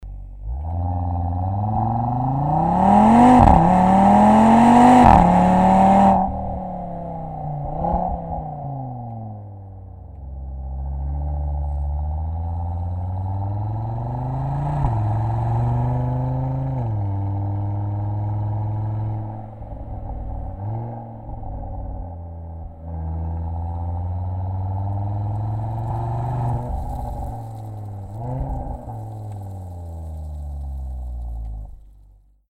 Audi_RS3_OPF_REMUS_VSD_Ersatzrohr_ESD.mp3